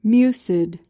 PRONUNCIATION: (MYOO-sid) MEANING: adjective: 1.